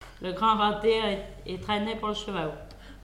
Mémoires et Patrimoines vivants - RaddO est une base de données d'archives iconographiques et sonores.
Langue Maraîchin
Catégorie Locution